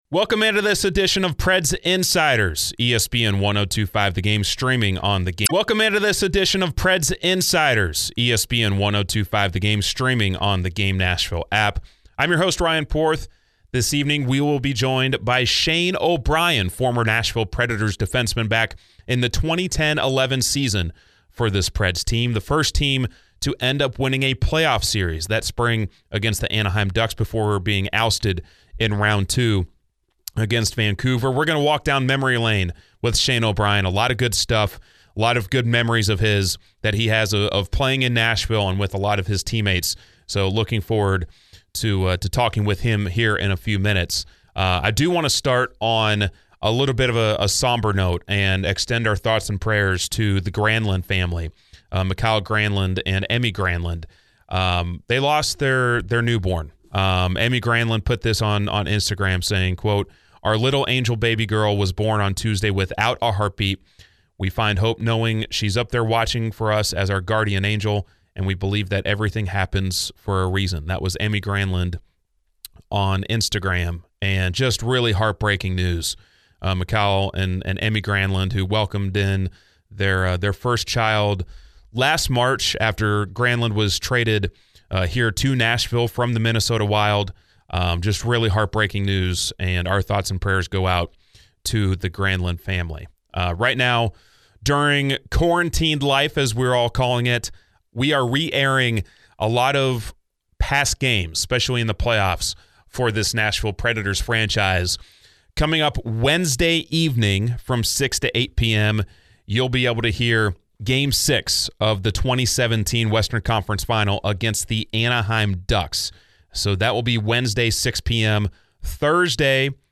Interview begins at the 3:35 mark of the podcast.